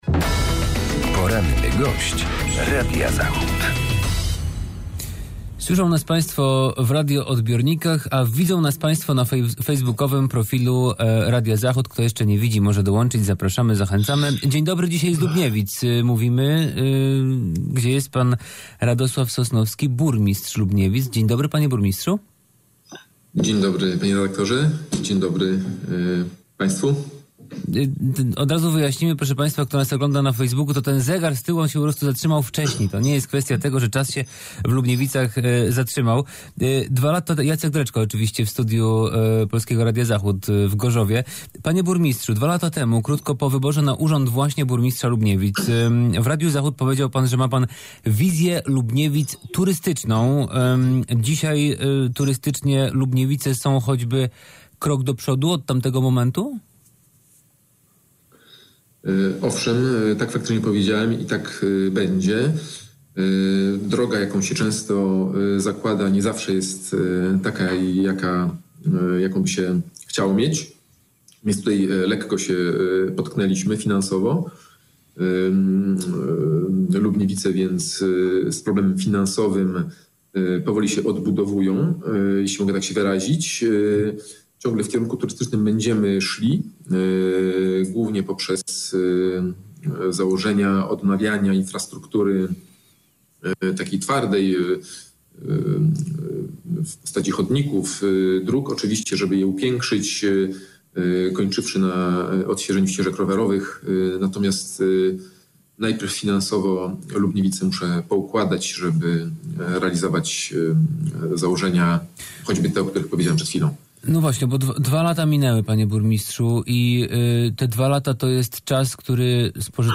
Z burmistrzem Lubniewic